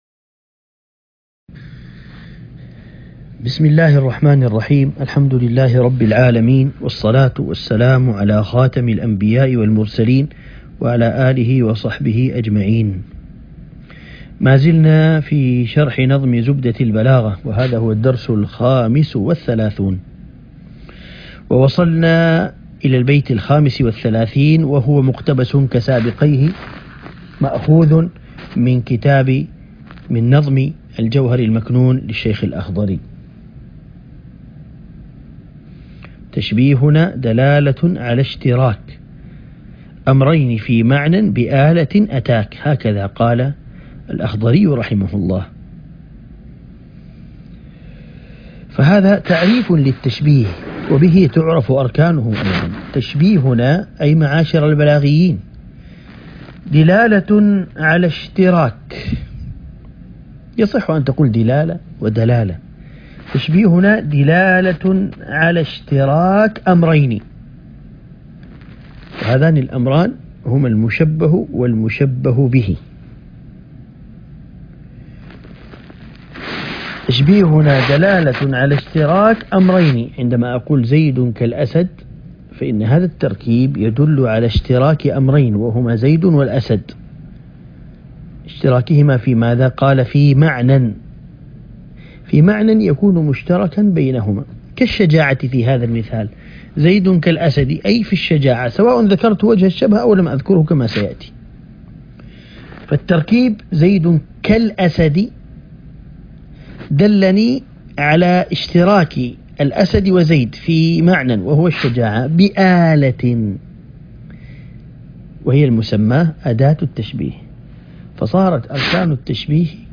عنوان المادة الدرس ( 35) شرح نظم زبدة البلاغة